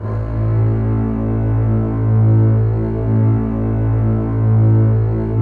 Index of /90_sSampleCDs/Optical Media International - Sonic Images Library/SI1_Lush Strings/SI1_Slow Lush